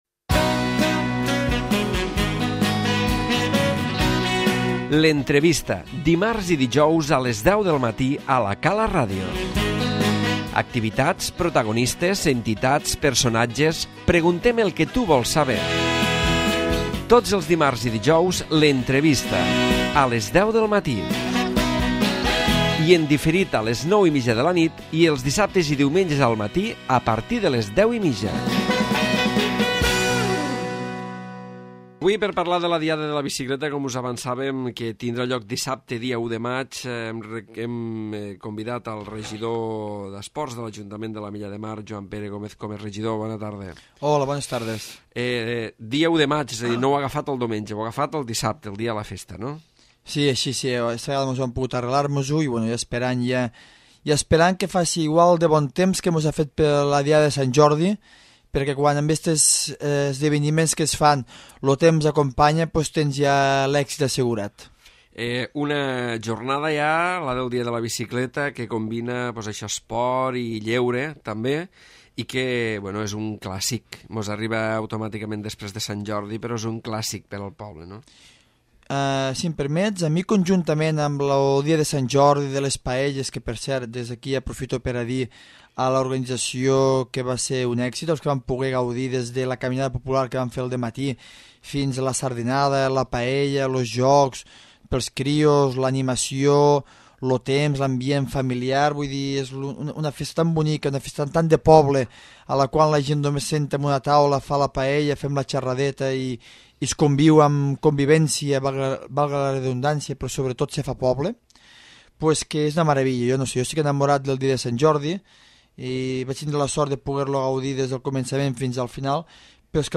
L'Entrevista
El regidor d'esports de l'Ajuntament de l'Ametlla de Mar, Joan Pere Gómez Comes explica els detalls de la Diada de la Bicicleta, que aquest any arriba a la 38ª edició i que com és tradicional, tindrà lloc el pròxim dia 1 de maig a Mas Platé.